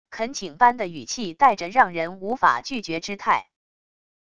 恳请般的语气带着让人无法拒绝之态wav音频